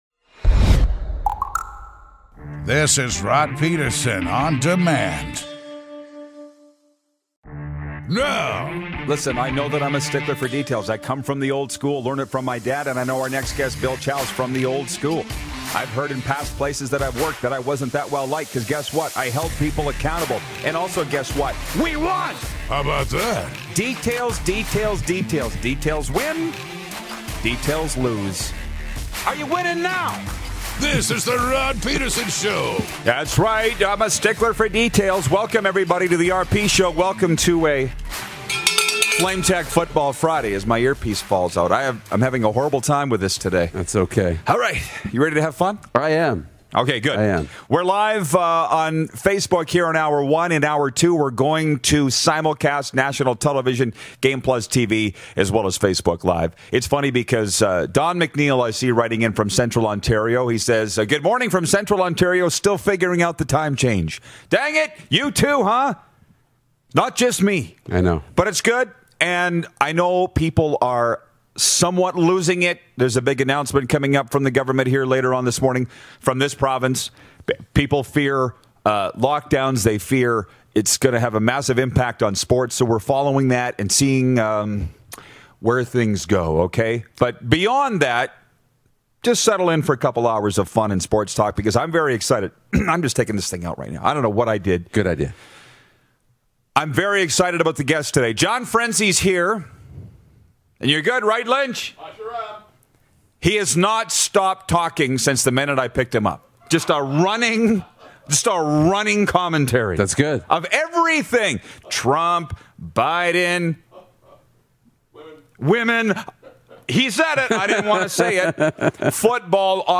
15 Year NFL QB and host of the “Huddle Up With Gus” Podcast… Gus Frerotte checks in during Hour 1!